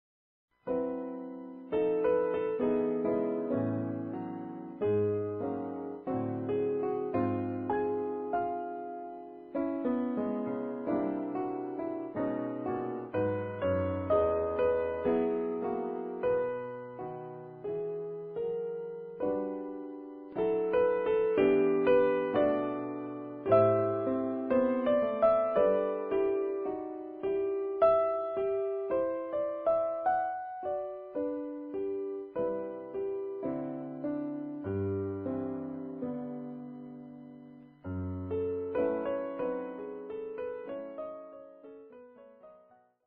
jazzy in character